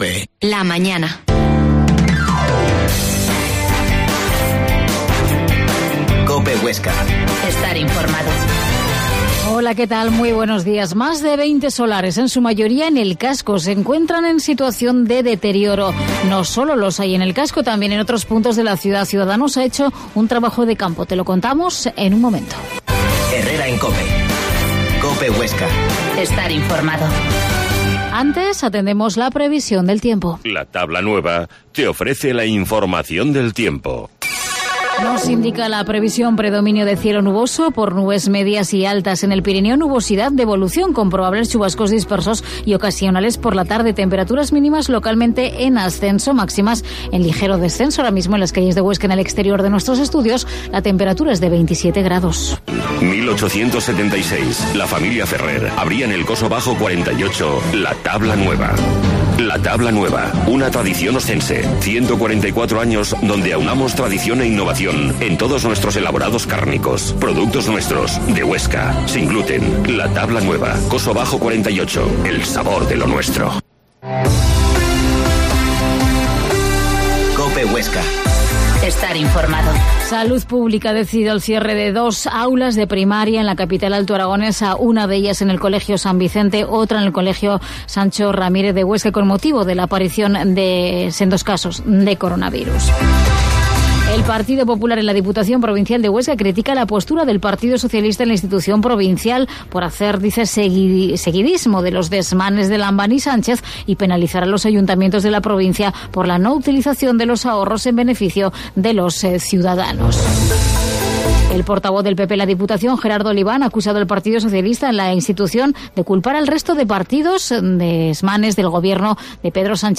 Herrera en COPE Huesca 12.50h Entrevista a la concejal de Ciudadanos en el Ayuntamiento, Mª Eugenia Gabás